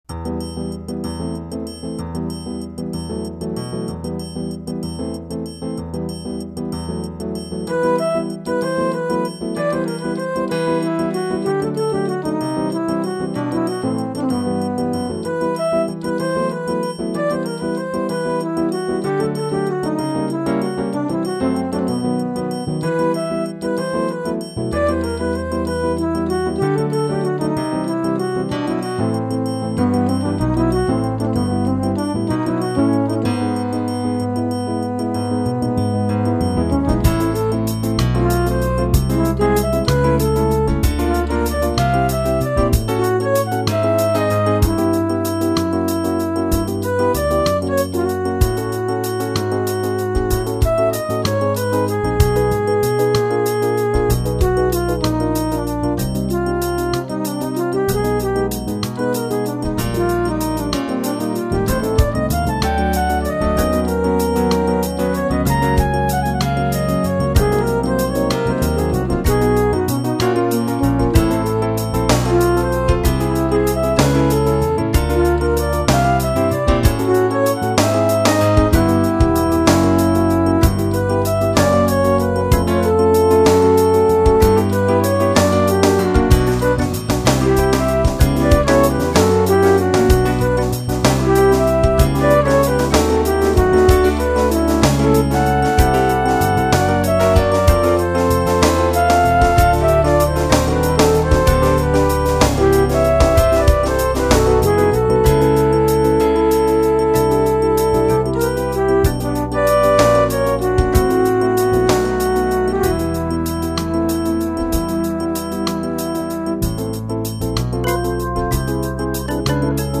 Arrangement (with added solo) and midi recording
Sorry about the midi, but this tune deserves a listen.